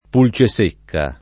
pulcesecca
vai all'elenco alfabetico delle voci ingrandisci il carattere 100% rimpicciolisci il carattere stampa invia tramite posta elettronica codividi su Facebook pulcesecca [ pul © e S% kka ] s. f.; pl. pulcisecche o pulcesecche